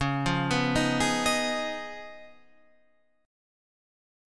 C# 9th